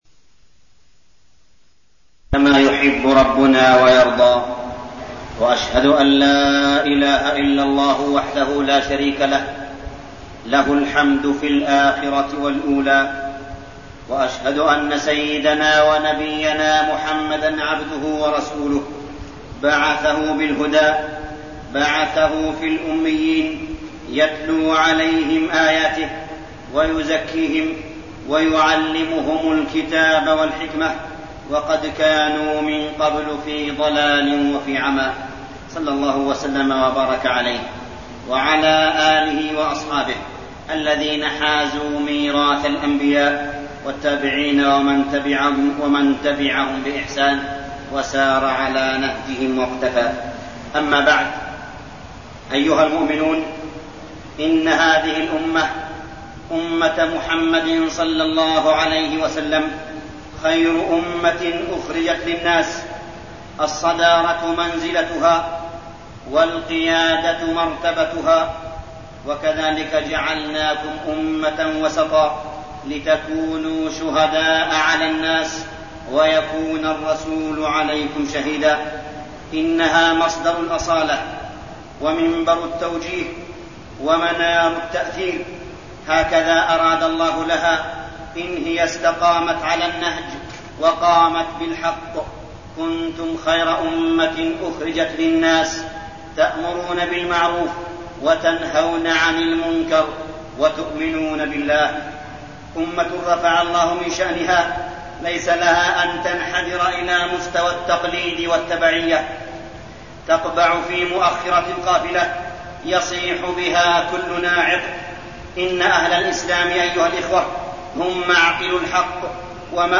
تاريخ النشر ١٥ صفر ١٤١٠ هـ المكان: المسجد الحرام الشيخ: معالي الشيخ أ.د. صالح بن عبدالله بن حميد معالي الشيخ أ.د. صالح بن عبدالله بن حميد مناهج التربية